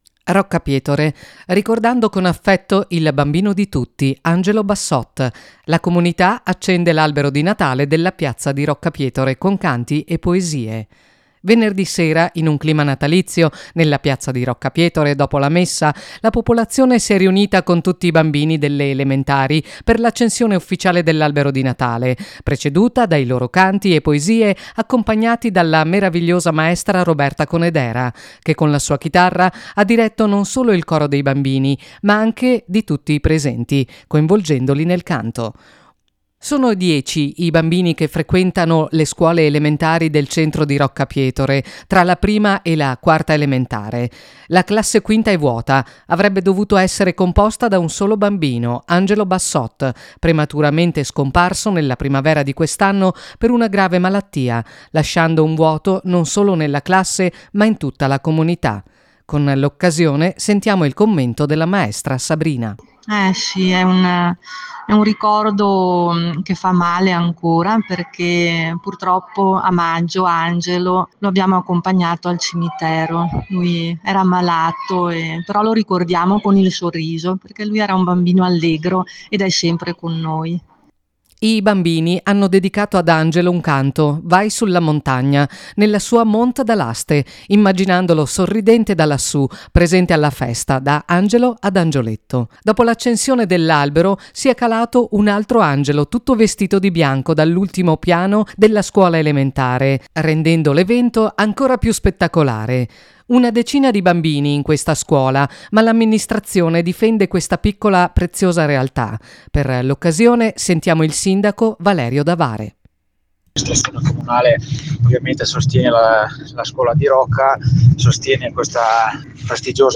In occasione dell’evento di venerdì scorso, con l’accensione dell’albero, e con la scuola elementare “aperta per ferie”, per ospitare tutta la comunità per godere dello spettacolo offerto dai 10 bambini frequentanti dalla classe 1 alla 4, il primo cittadino, Valerio Davare, dichiara che l’amministrazione del comune, ritiene importante sostenere la scuola, come azione di contrasto contro lo spopolamento, attraverso finanziamenti per dare continuità a varie attività come ad esempio il doposcuola.